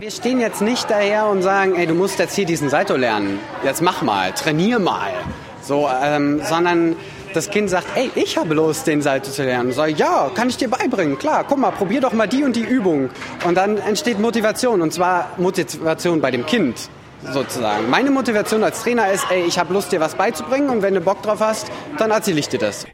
O-Töne / Radiobeiträge, , ,